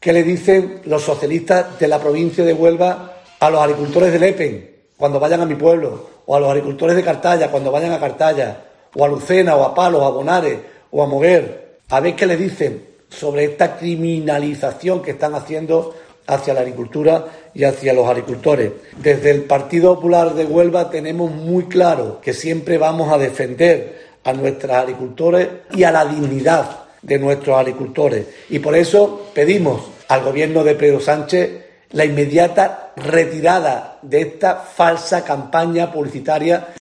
Manuel Andrés González, presidente del PP de Huelva